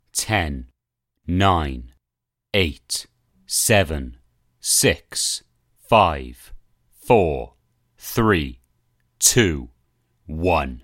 倒计时
描述：101倒计时勒芒的声音
标签： 声音 人性化 言语 倒计时 声音 口语 单词
声道立体声